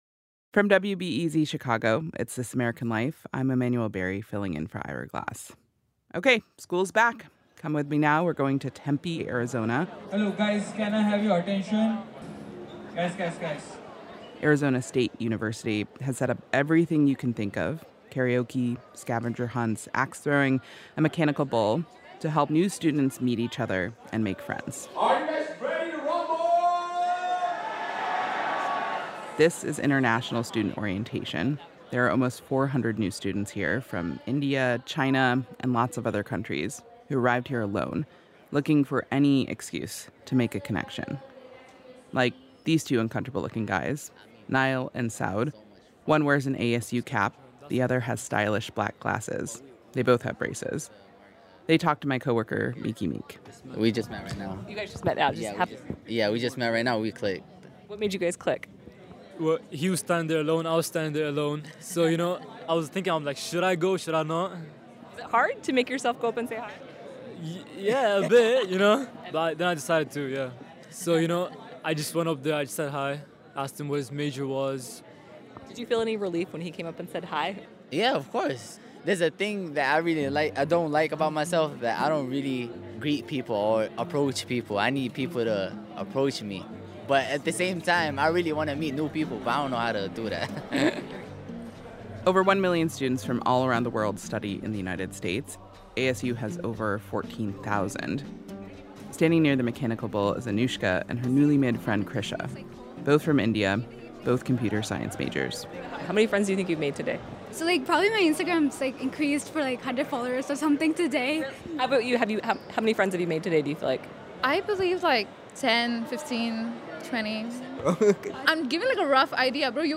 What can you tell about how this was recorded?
Note: The internet version of this episode contains un-beeped curse words.